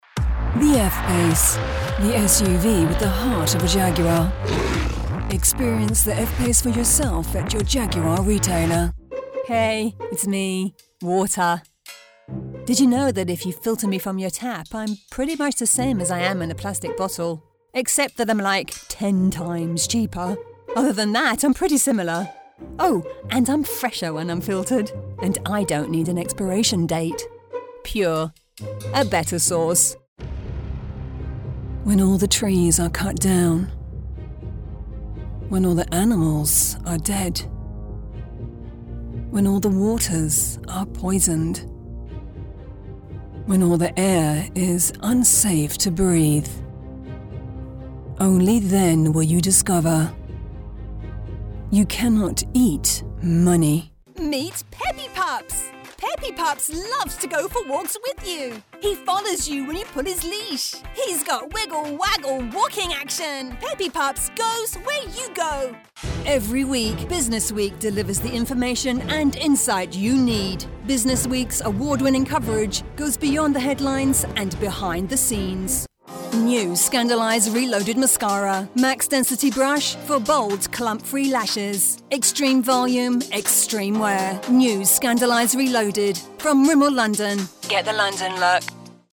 British Female Voice Over Commercial Demo
Voz Madura 01:28
- Broadcast quality recording from her professional home studio